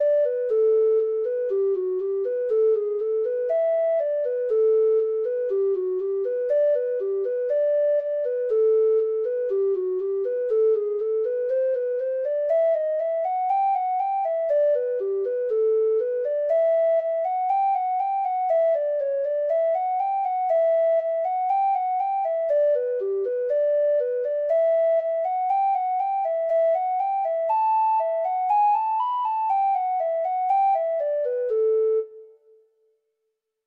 Traditional Music of unknown author.
Reels